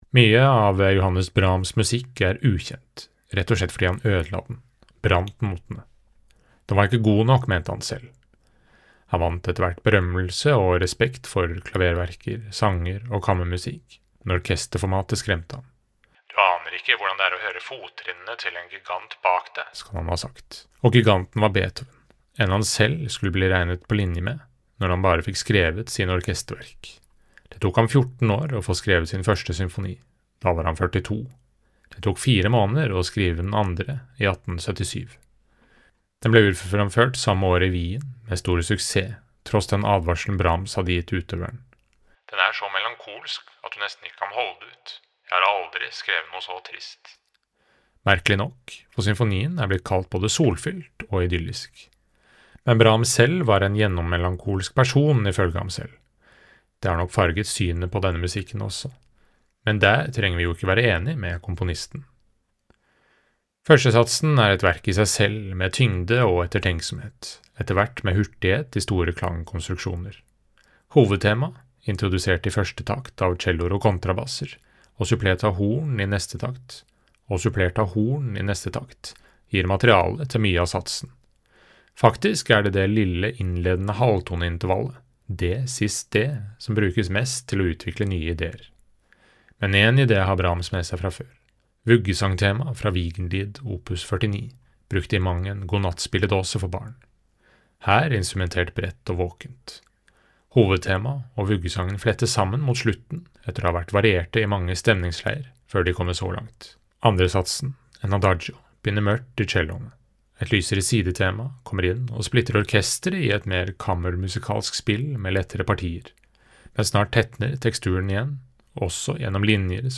VERKOMTALE: Johannes Brahms' Symfoni nr. 2
VERKOMTALE-Johannes-Brahms-Symfoni-nr.-2.mp3